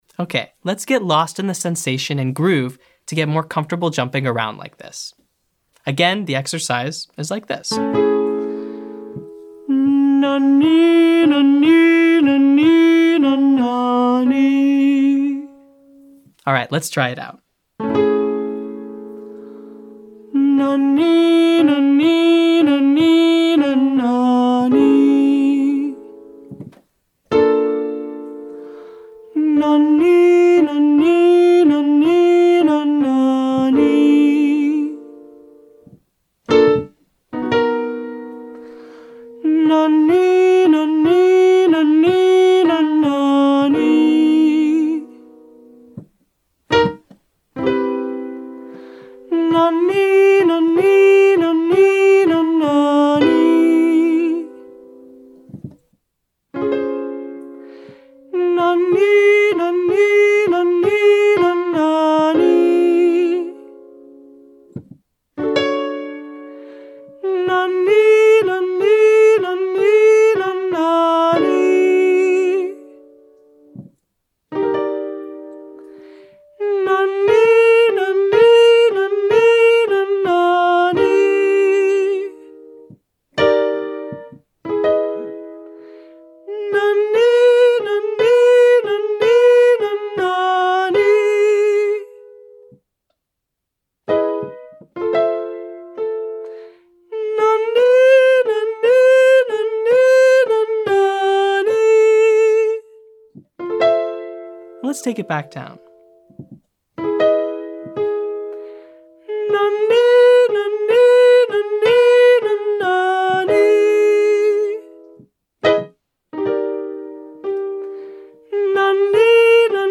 This type of mix allows us to sing softer and with lots of vocal ease.
Let’s use a lighter groove style exercise that starts with switching registers a little closer together in pitch than Day 9 to warm up our mix.